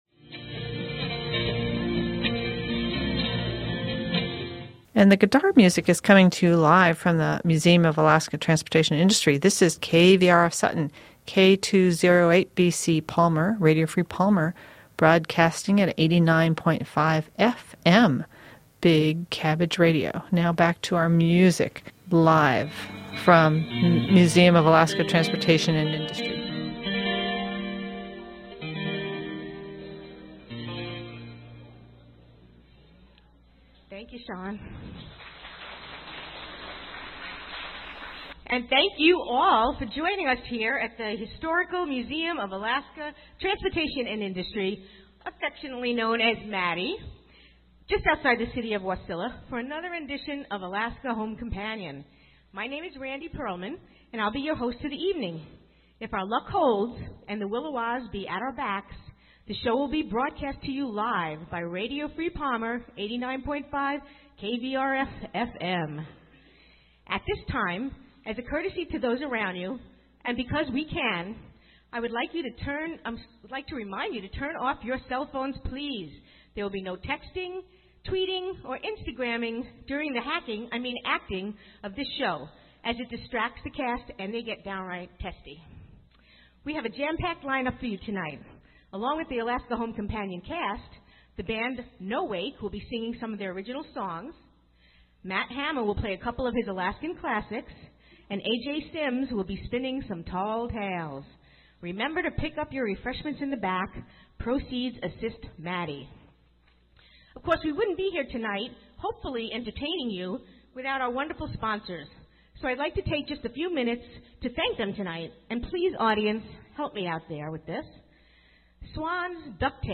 Alaska Home Companion from the Museum of Alaska Transportation and Industry 10.19.2013
The majority of the show is captured in this recording. We apologize for the occasional gaps.